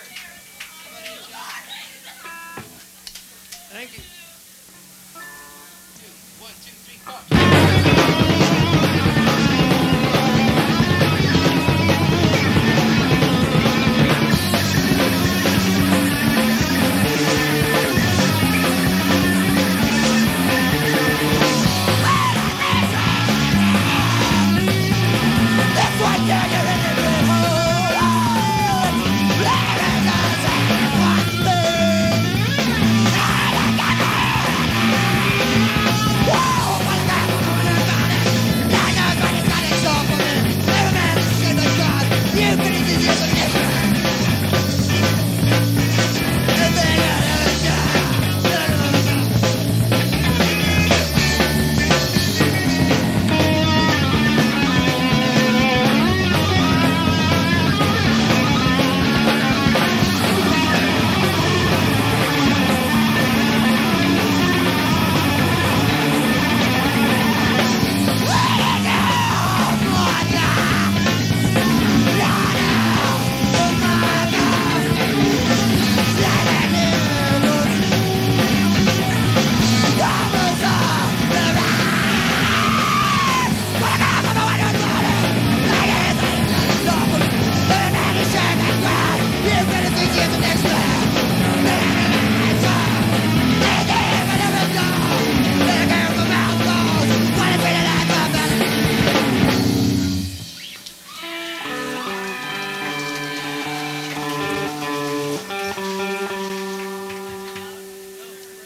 when the sound was raw